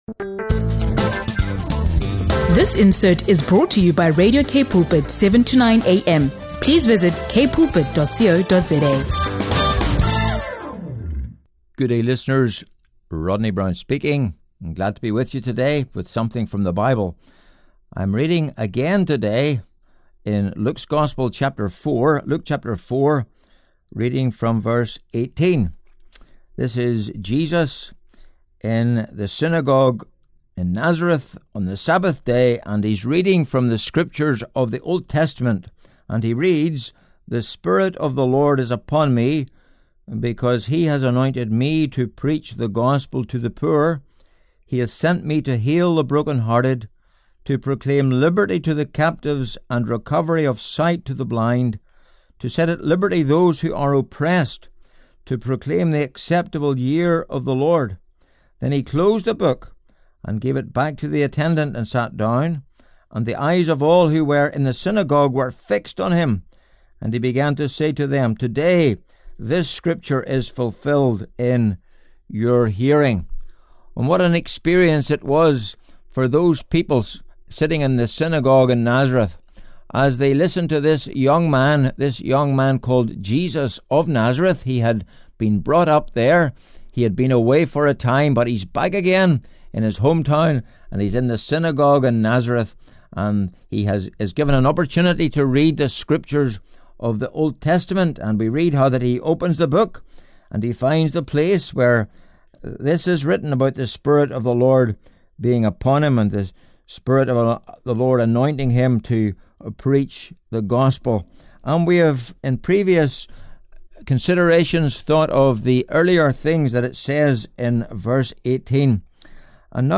SERMONS & PREKE